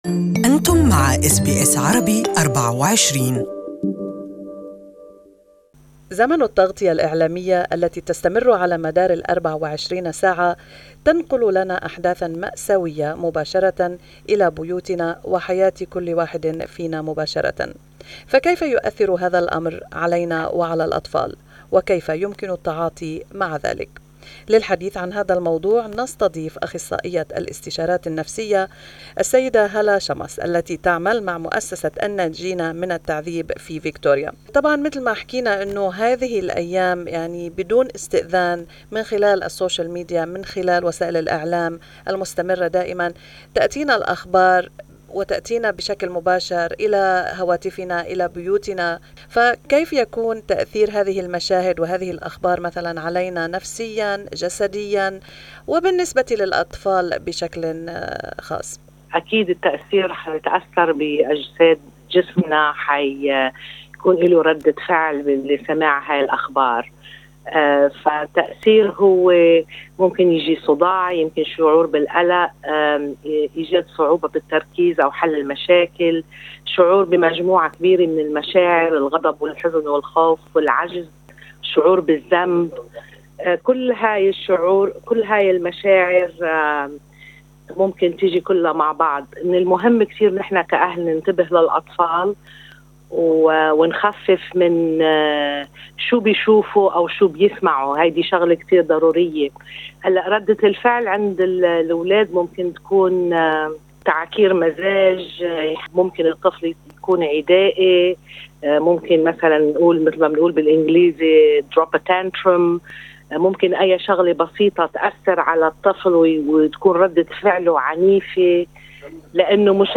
في هذا اللقاء